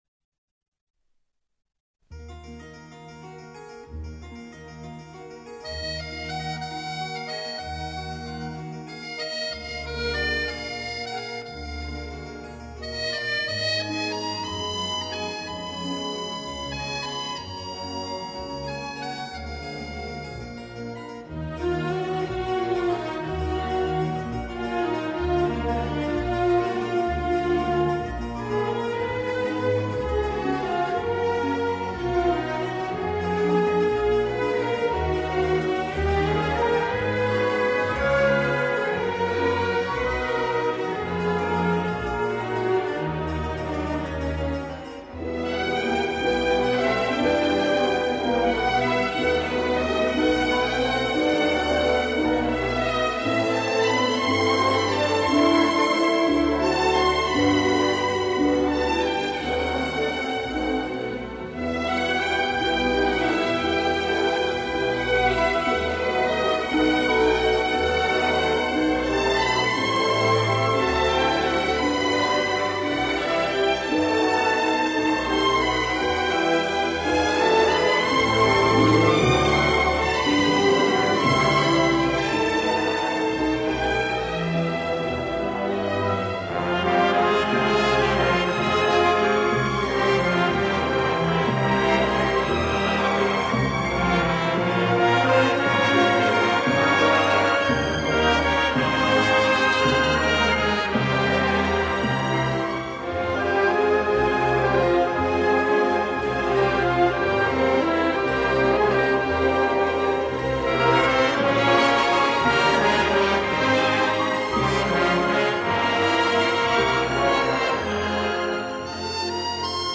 موزیک بی کلام